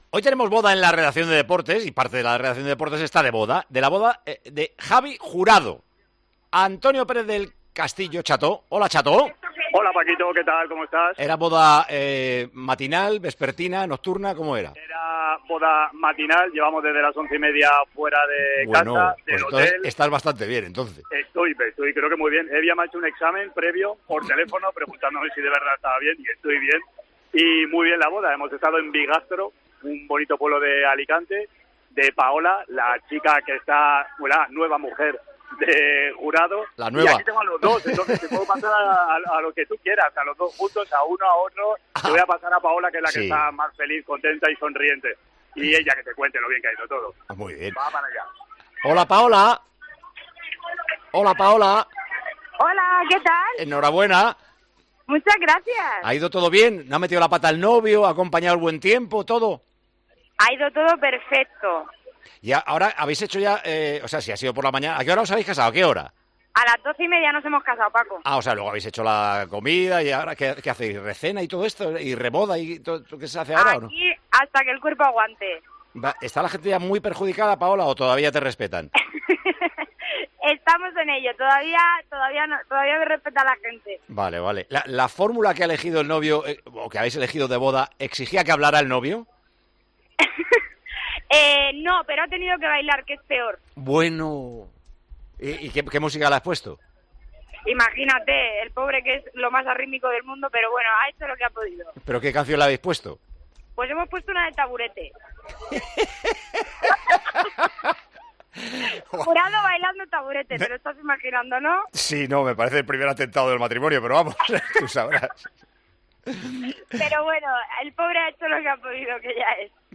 AUDIO: Hablamos en Tiempo de Juego con el novio y la novia de este feliz evento.